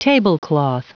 Prononciation du mot tablecloth en anglais (fichier audio)
Prononciation du mot : tablecloth